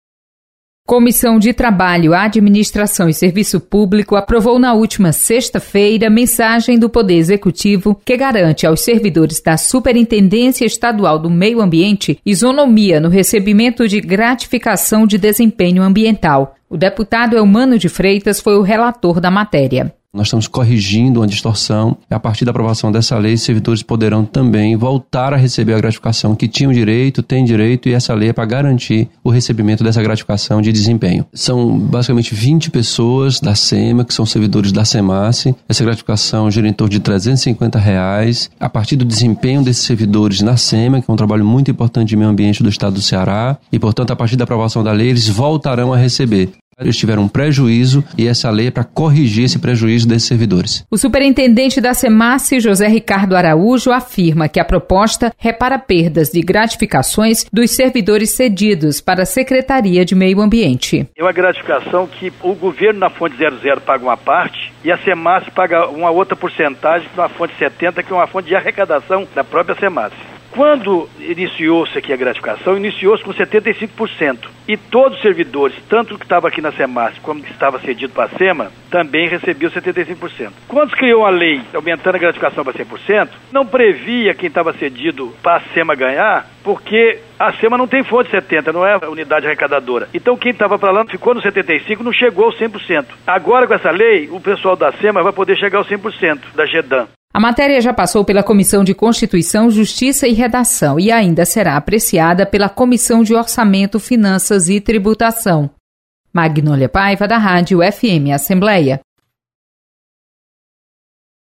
Comissão aprova isonomia na gratificação de servidores da Semace. Repórter